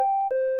connection_stoped_sepura.mp3